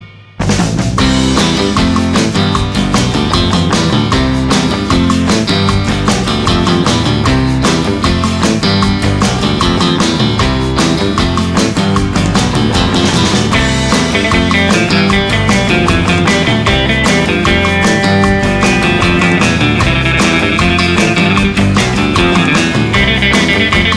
no Backing Vocals Rock 'n' Roll 2:29 Buy £1.50
Rock 'n' Roll